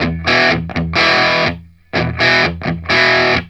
RIFF1-125E.A.wav